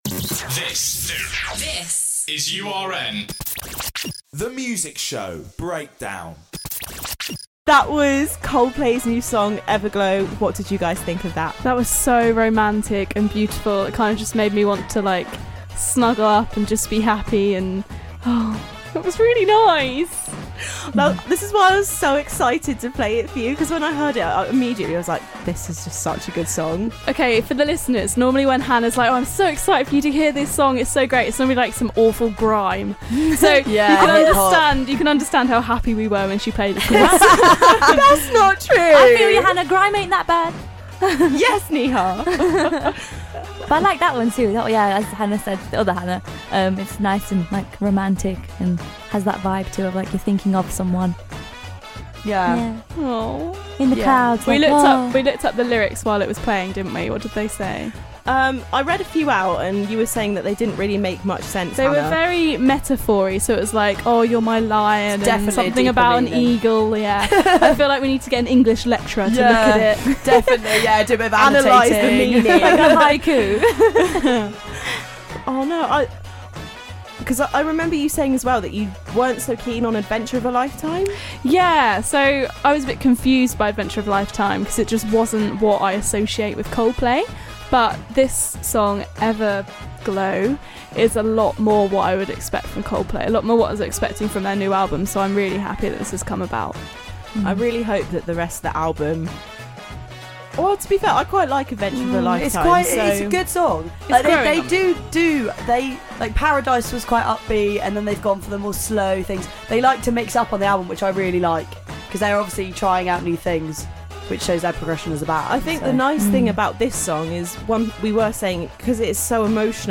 This week we have a chat about Coldplay's new single Everglow and discuss the BBC's plans for a new weekly TV chart show, as well as being joined in the studio by Radio Octave from the Acapella society performing Pompeii.